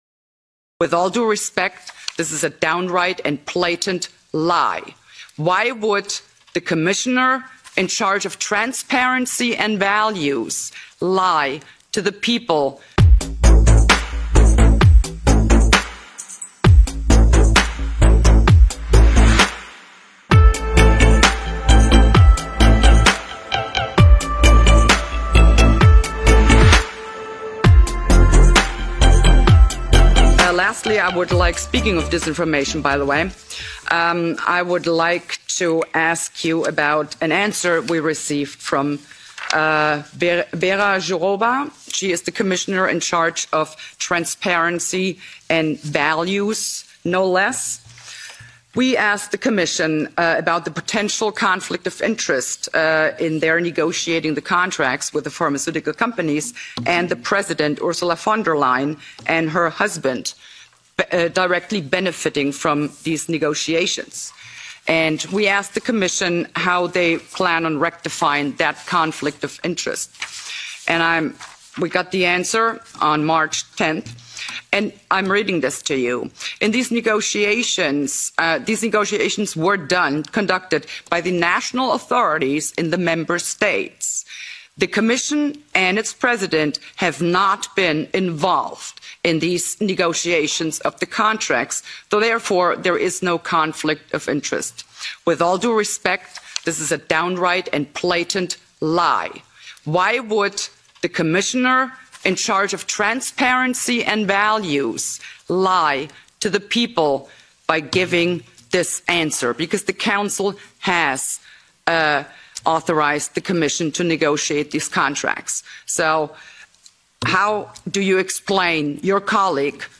In der jüngsten Sitzung des COVID-19 EU-Sonderausschuss befragte Anderson daher ihre Kollegin Stella Kyriakides (EU-Kommissarin für Gesundheit und Lebensmittelsicherheit), was sie zu dieser Lüge zu sagen hat. Frau Kyriakides verweigerte daraufhin jede Antwort und die Ausschussvorsitzende machte dazu noch Witze, dass Politik halt nun mal so sei.